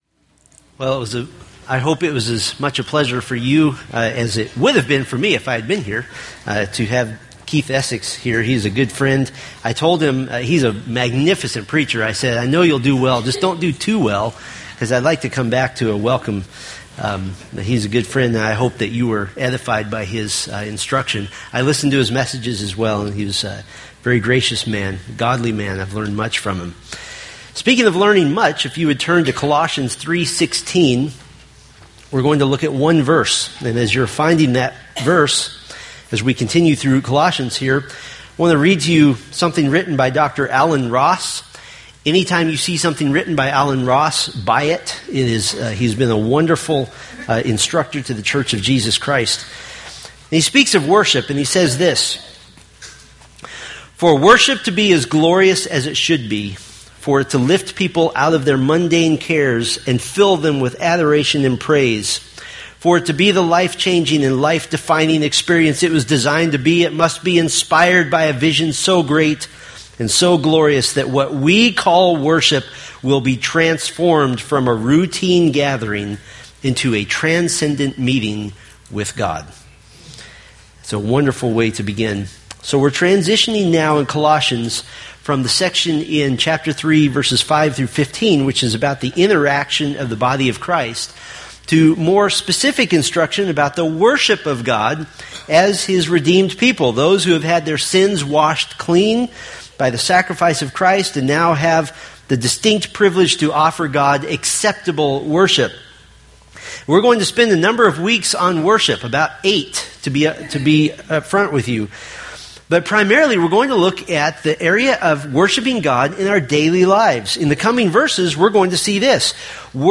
From the Worshiping Christ in Daily Life sermon series.